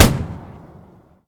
mg-shot-4.ogg